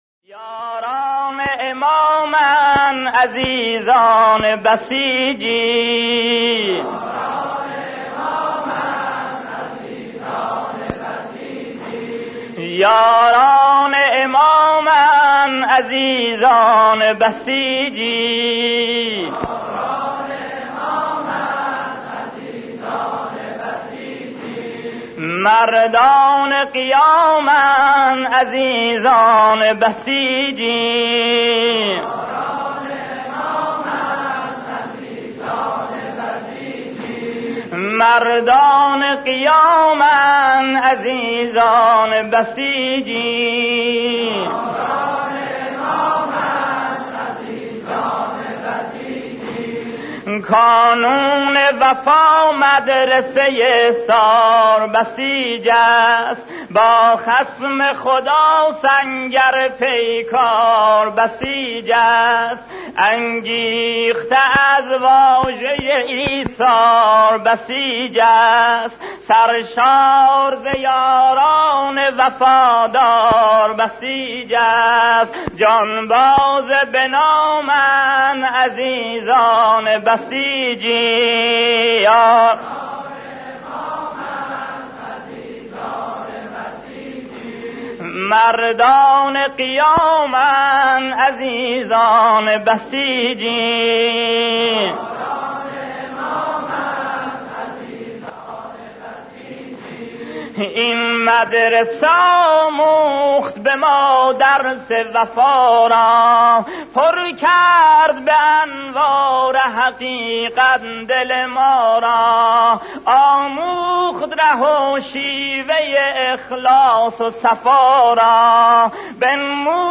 ساجد >> صوت